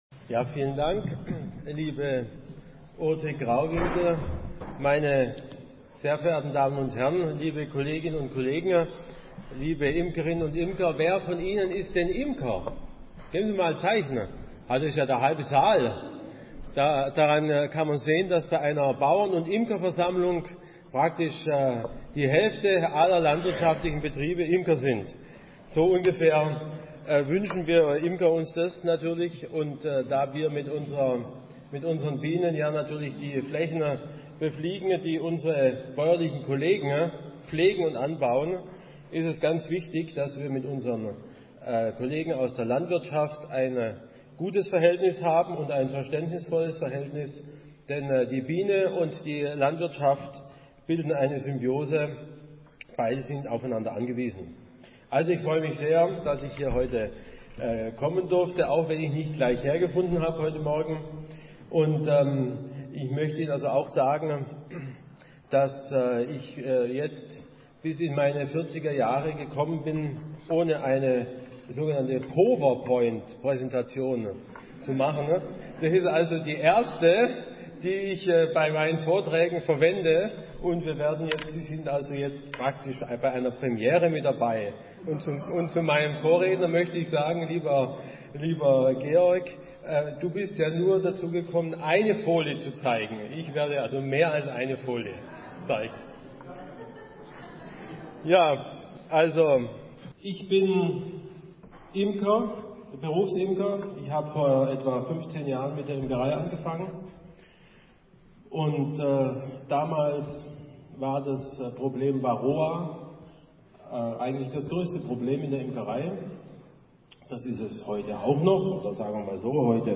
Download: Mitschnitt des Vortrags (mp3-Datei,3,1 MB)